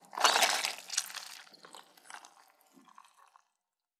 Blood_24.wav